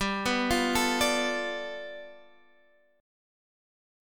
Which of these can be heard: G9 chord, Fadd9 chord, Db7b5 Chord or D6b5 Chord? G9 chord